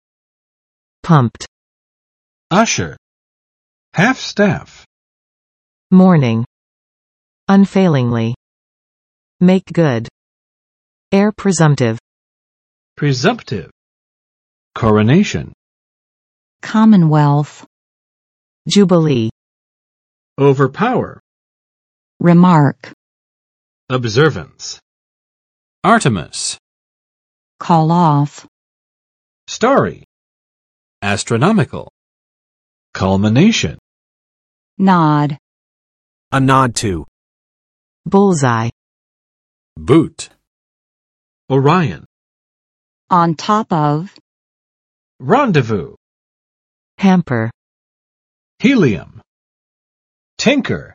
[pʌmpt] (up) adj. 热情高涨的; 高度兴奋的